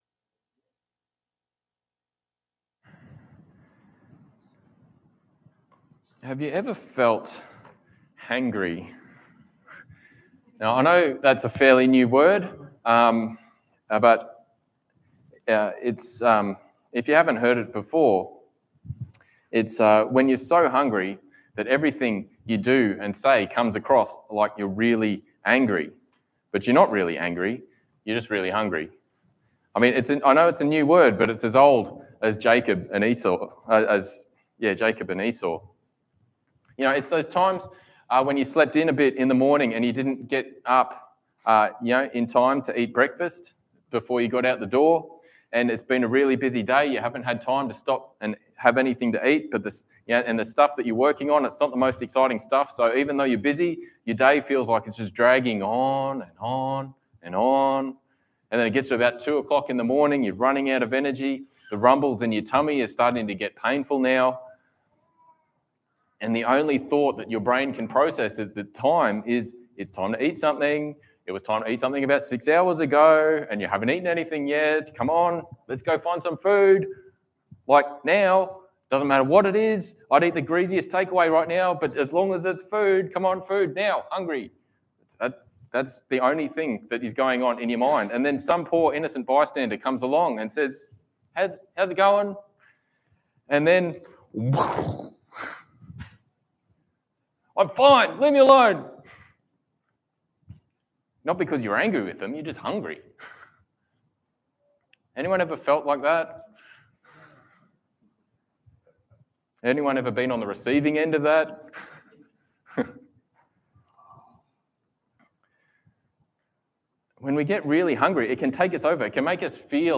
Passage: John 6:27-40 Service Type: Sunday Morning